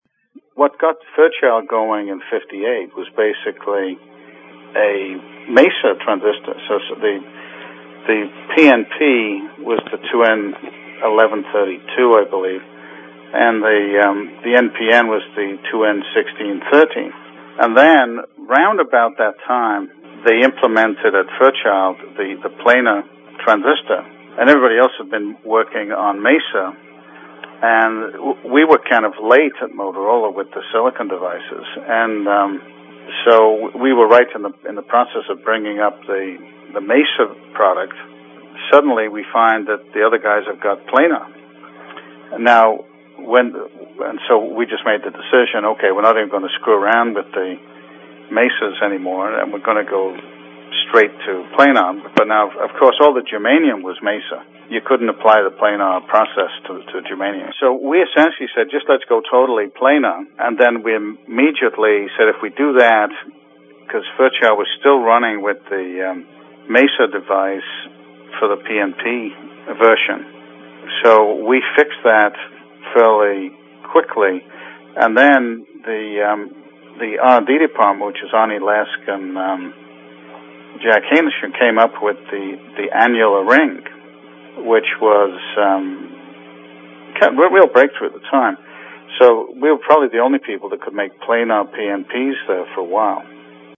A Transistor Museum Interview